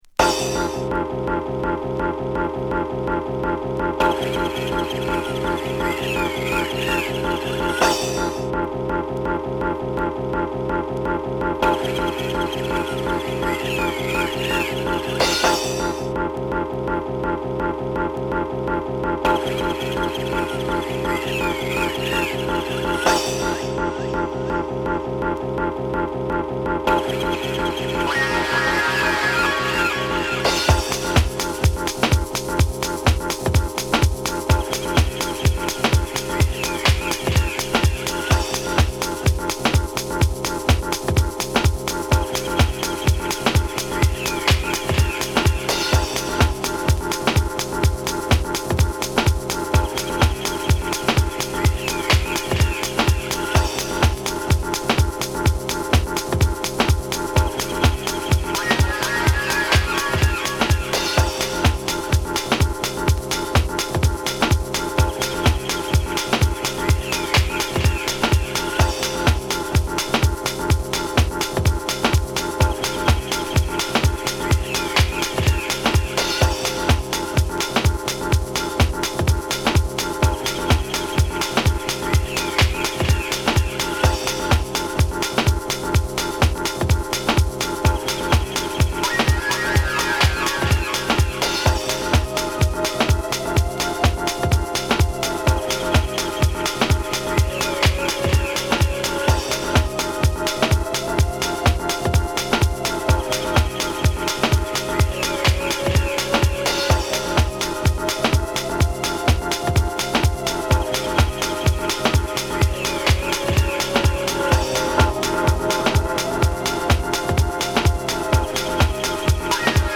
Broken Beats , Deep House